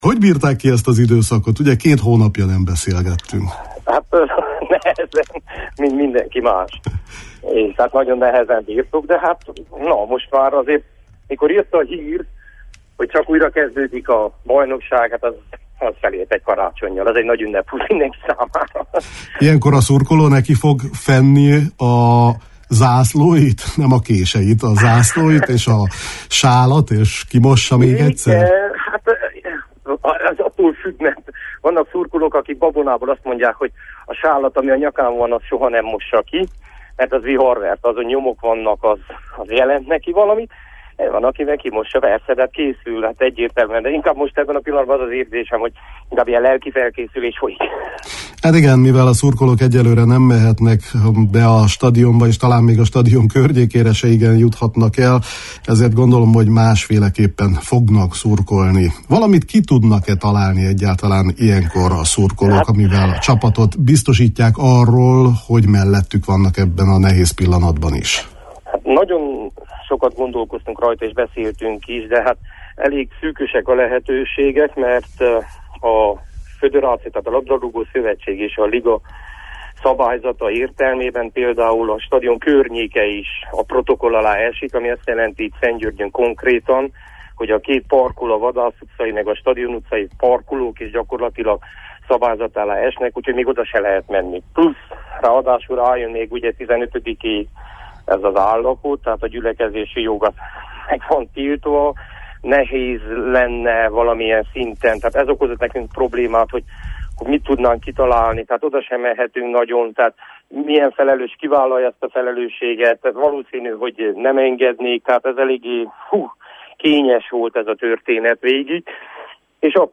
A teljes beszélgetést meghallgathatják itt: